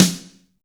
TAMA AMB SD.WAV